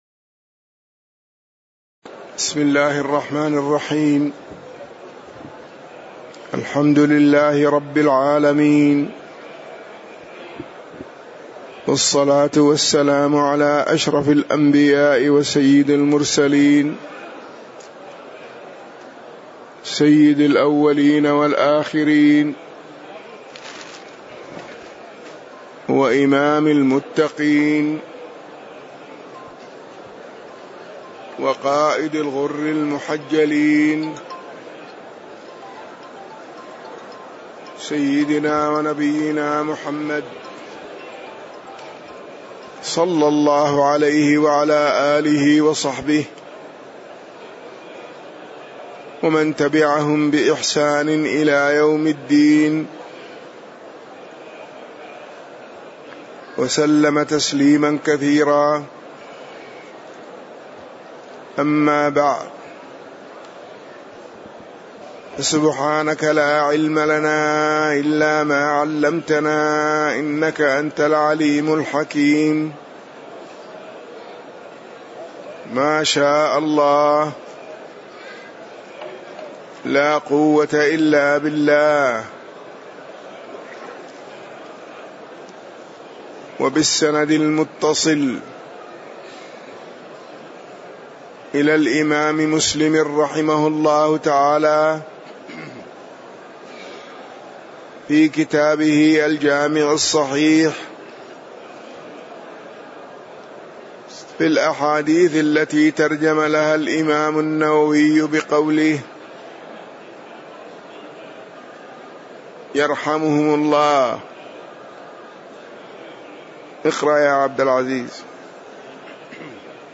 تاريخ النشر ٩ شعبان ١٤٣٧ هـ المكان: المسجد النبوي الشيخ